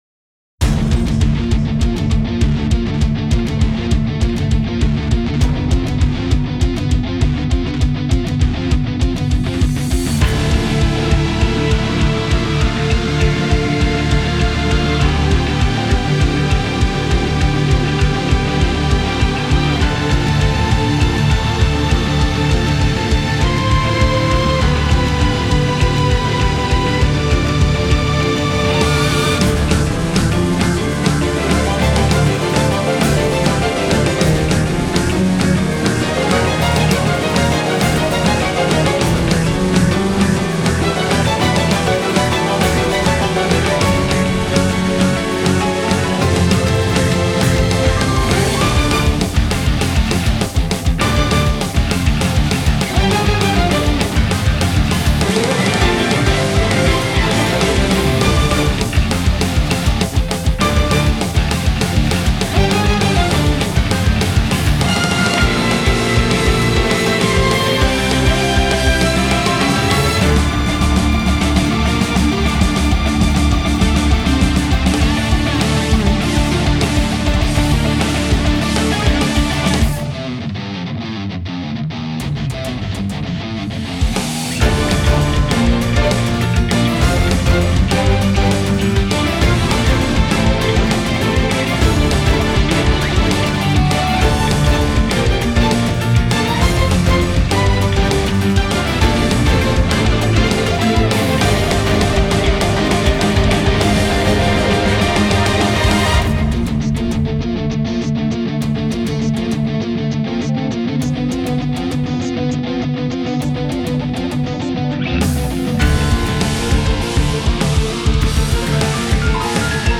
موسیقی اینسترومنتال موسیقی بی کلام